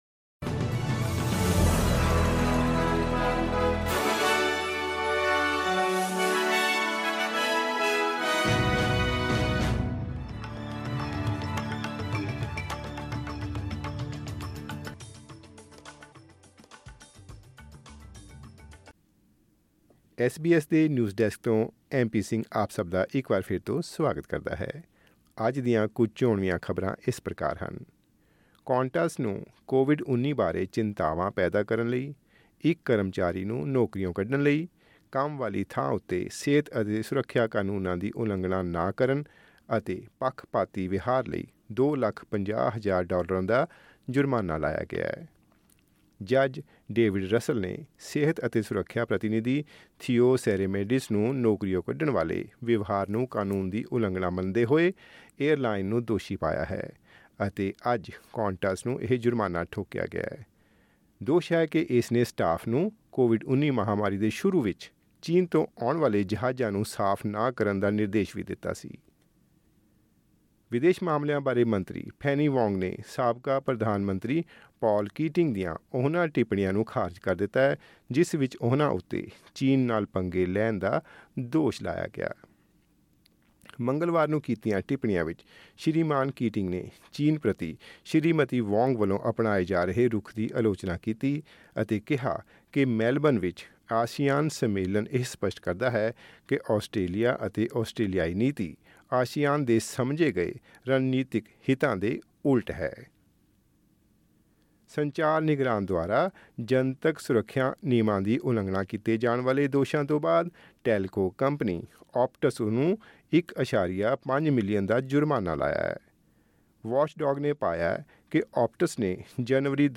ਐਸ ਬੀ ਐਸ ਪੰਜਾਬੀ ਤੋਂ ਆਸਟ੍ਰੇਲੀਆ ਦੀਆਂ ਮੁੱਖ ਖ਼ਬਰਾਂ: 6 ਮਾਰਚ, 2024